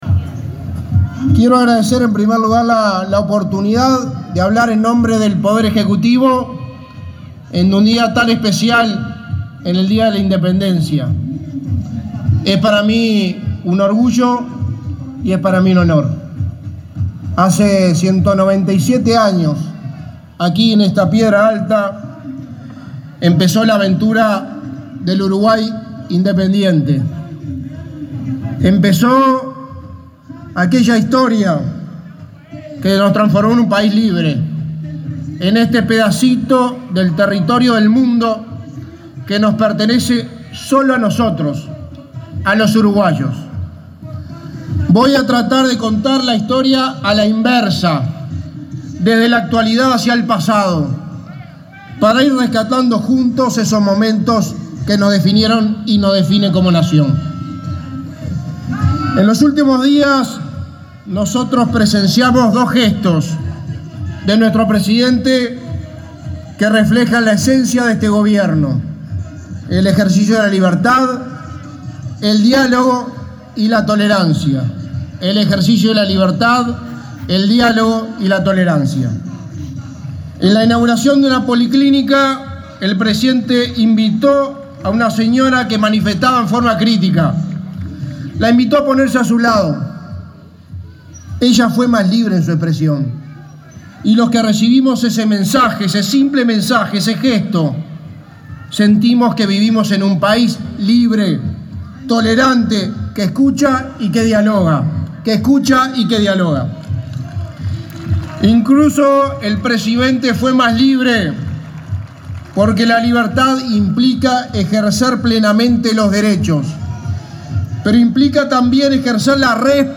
Palabras del secretario de Presidencia, Álvaro Delgado 25/08/2022 Compartir Facebook X Copiar enlace WhatsApp LinkedIn El secretario de la Presidencia de la República, Álvaro Delgado, fue el orador central en el acto de conmemoración de un nuevo aniversario de la Declaratoria de la Independencia, en la Piedra Alta de Florida.